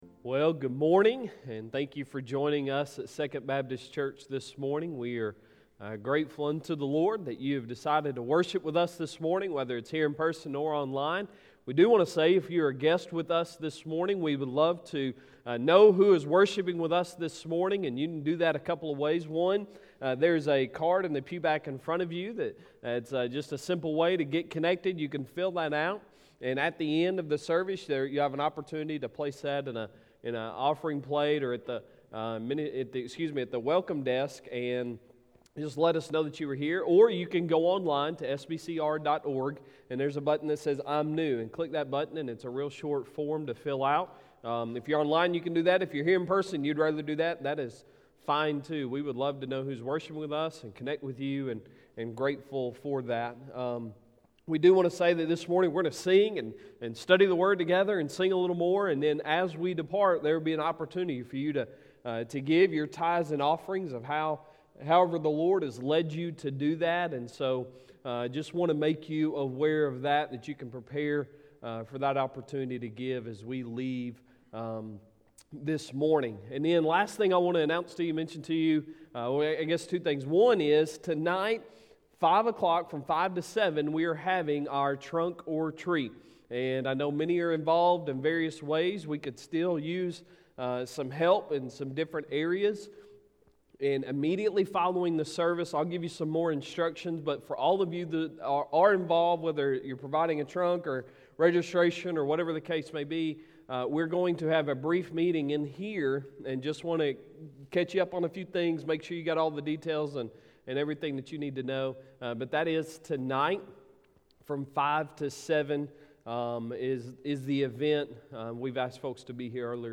Sunday Sermon October 25, 2020